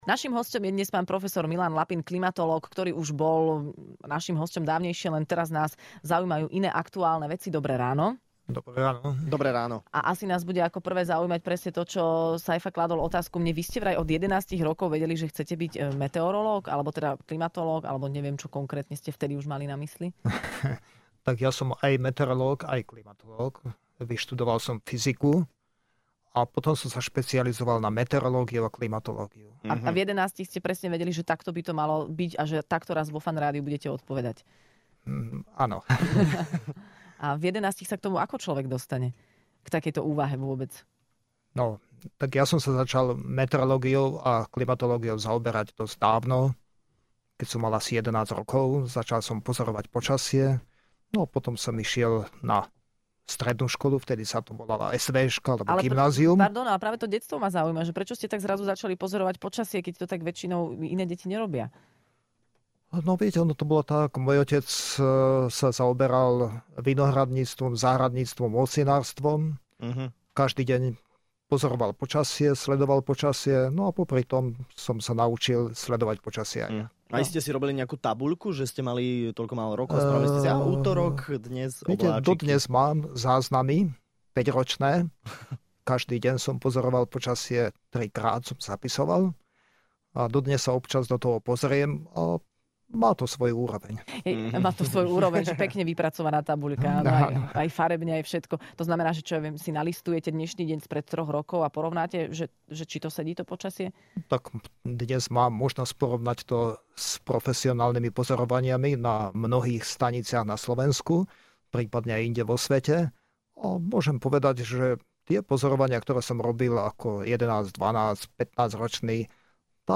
Počasie si s nami stále robí čo chce, a tak sme si do štúdia zavolali klimatológa